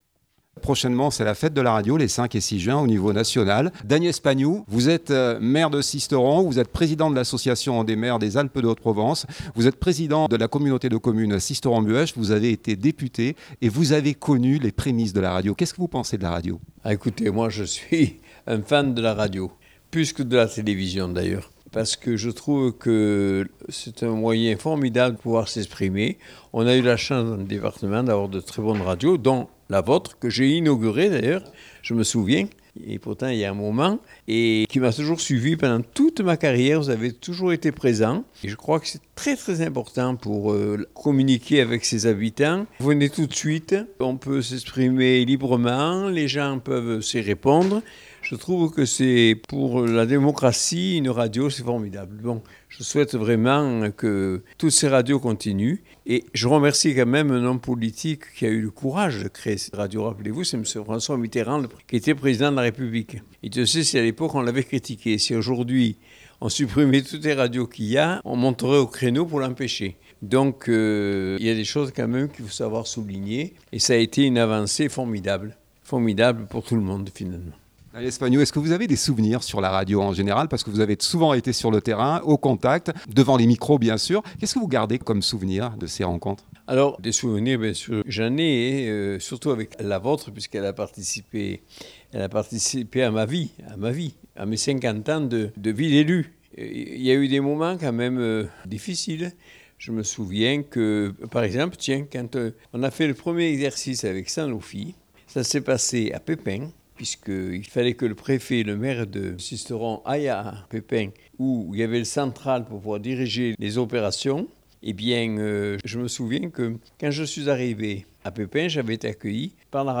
Fete de la Radio 2025 - L'interview du Maire de Sisteron
Le 5 et 6 juin 2025 c'était la fête de la Radio dans toute la France. A cette occasion, toute l'équipe de Fréquence Mistral s'est retrouvée afin de vous proposer un plateau délocalisé en direct sur toute la journée sur Manosque.